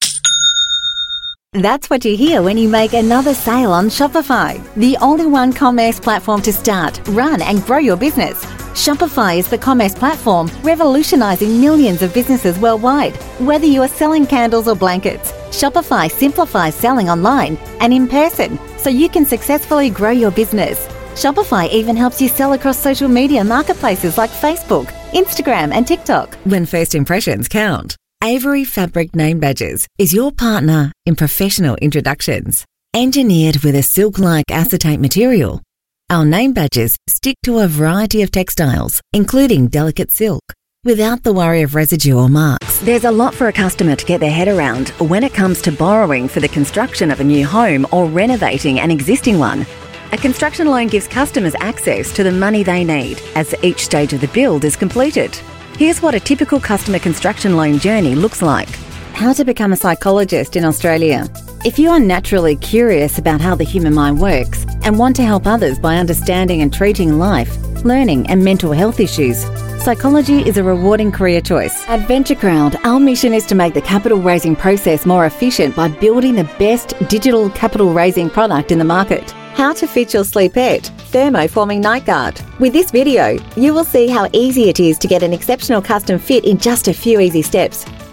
AU ENGLISH
Voice Sample: Explainer Demo